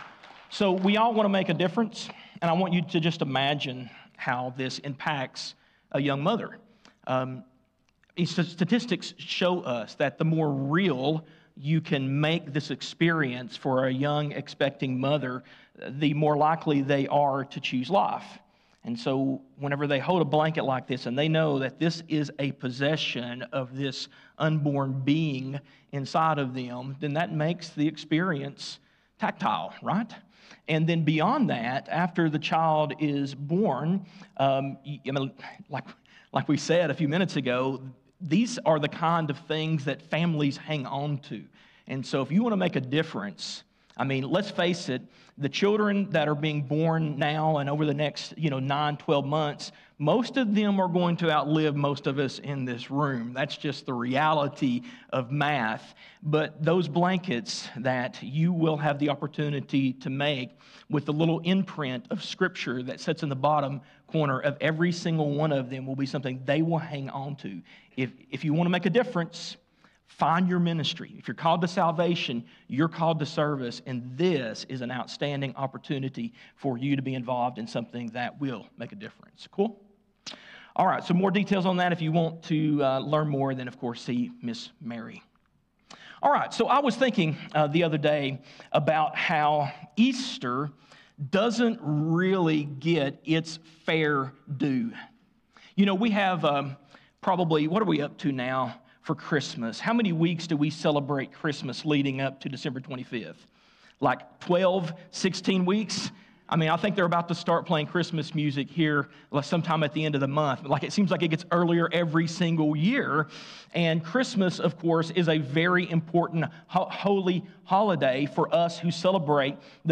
Christiansburg Baptist Church